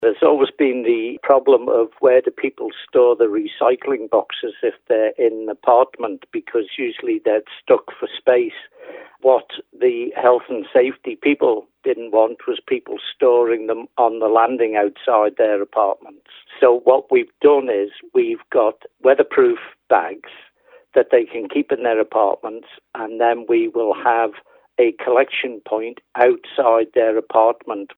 Environmental services committee chairman Ritchie McNicholl says in the past those living in flats faced barriers to going green: